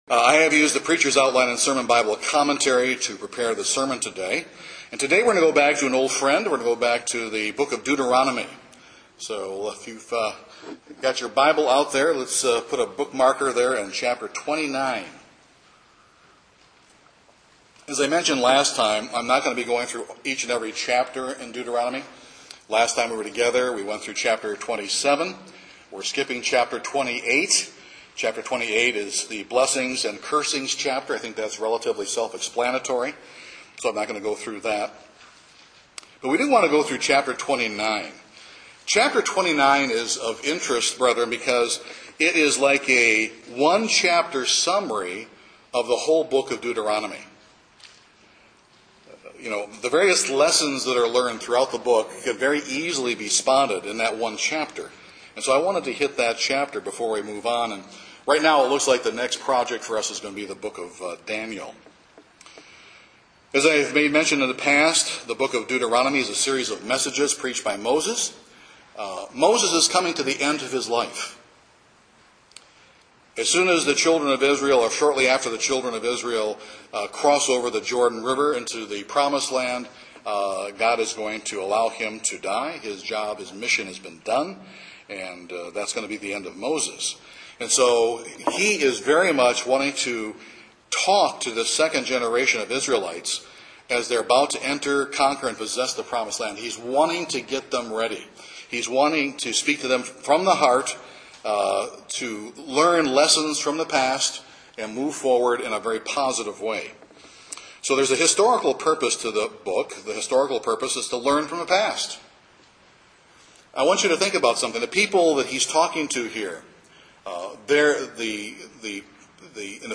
There are a number of parallels for the New Testament Christian that this sermon will make as well.